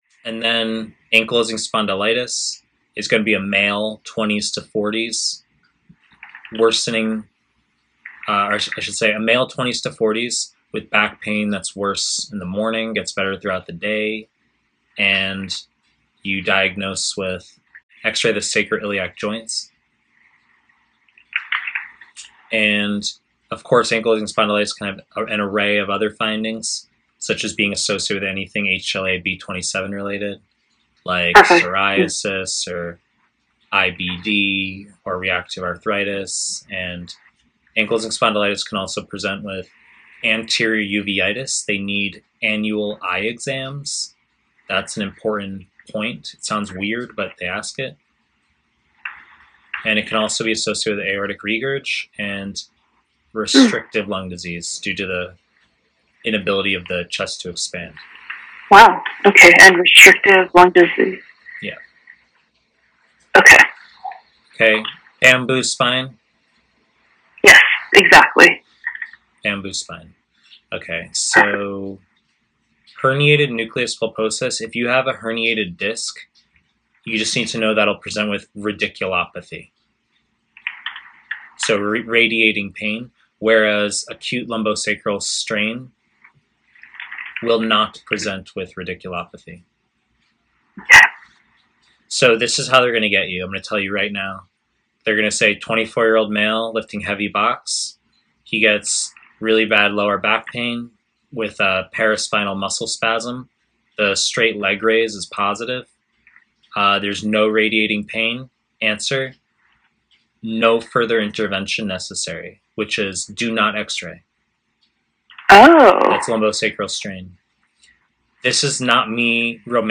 Family medicine / Pre-recorded lectures